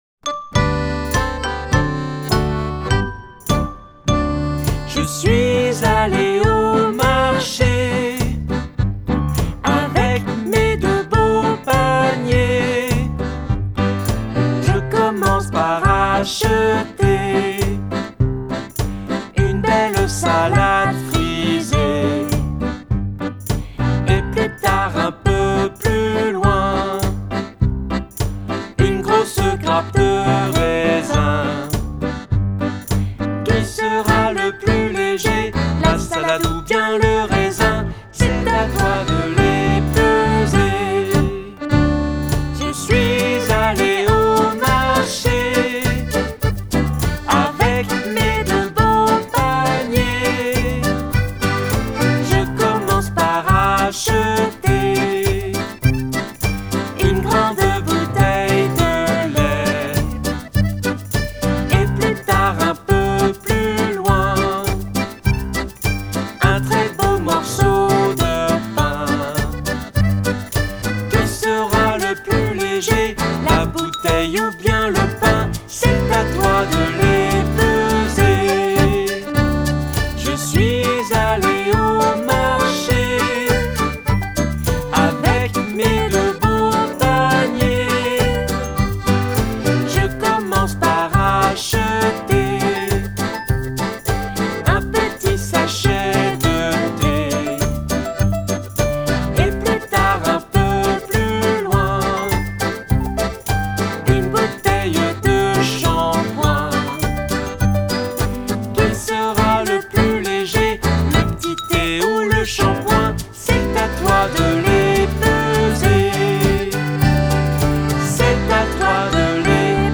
Comptines mathématiques